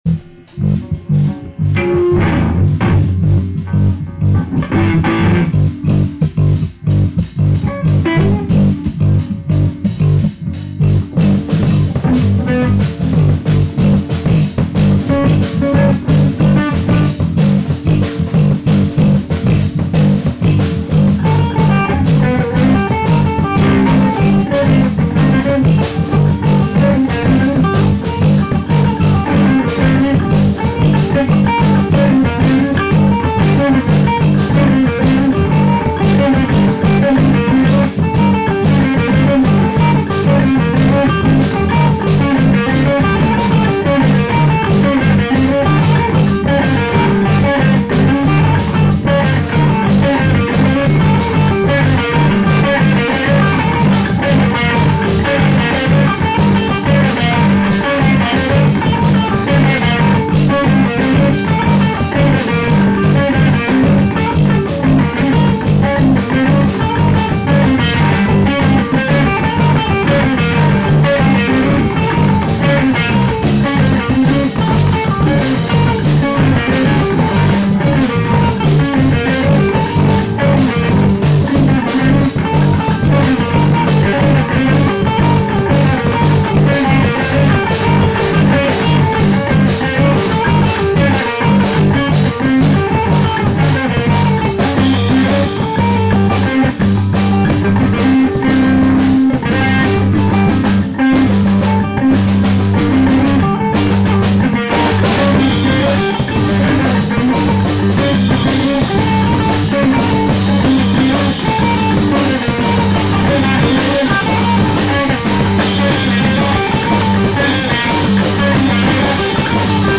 リハした
いまだ名前未定の新ドラマ―と3人で。
始めたセッションがよい感じ。
長いし、単調といえば単調なんだけど